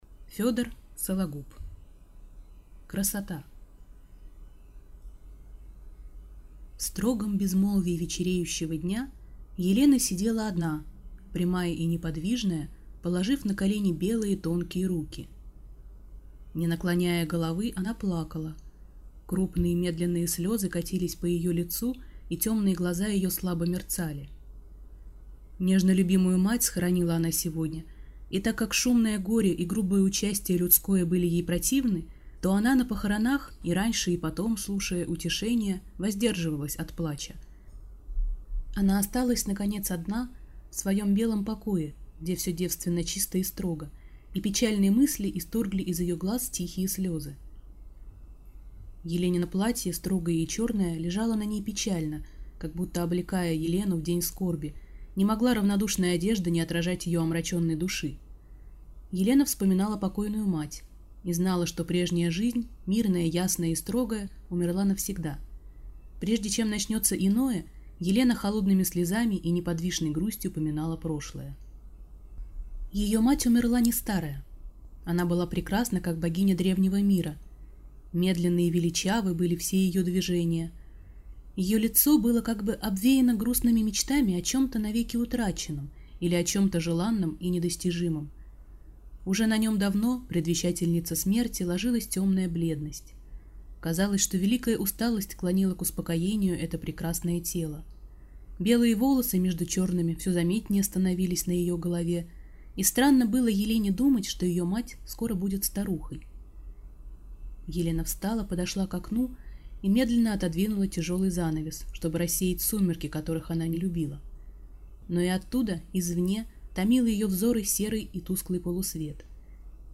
Аудиокнига Красота | Библиотека аудиокниг